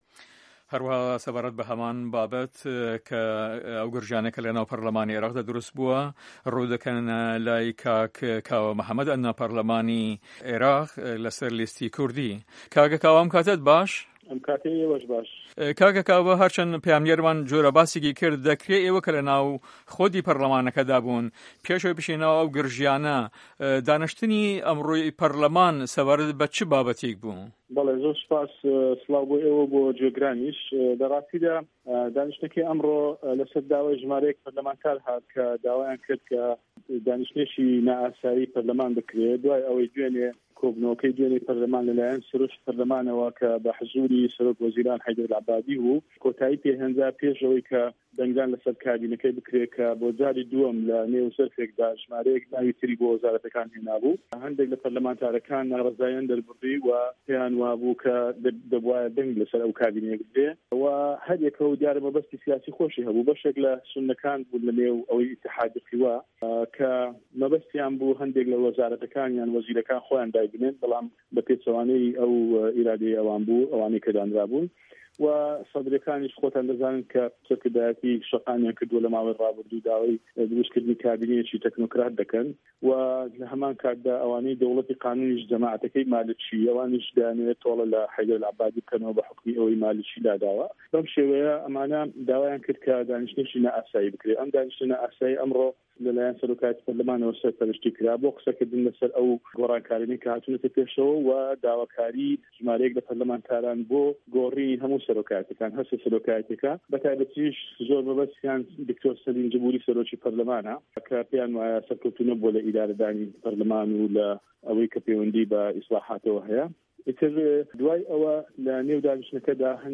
کاوە مەحەمەد ئەندام پەرلەمانی عێراق لەسەر لیستی فراکسیۆنی کوردستانی لە هەڤپەیڤینێکی راستەوخۆدا لەگەڵ بەشی کوردی دەنگی ئەمەریکادا ووتی" دانیشتنی نە ئاسایی ئەمرۆ لە سەر داواکاری هەندێ لە پەرلەمانتاران هات دووای ئەوەی کۆبونەوەکەی دوێنی پەرلەمان کە لە لایەن سەرۆکی پەرلەمان و بە ئامادە بوونی سەرۆک وەزیر حەیدەر عەبادی بوو و کۆتایی پێ هێنرا پێش ئەوەی دەنگدان لەسەر کابینەکەی بکرێ".
Interview with Kawa Muhamad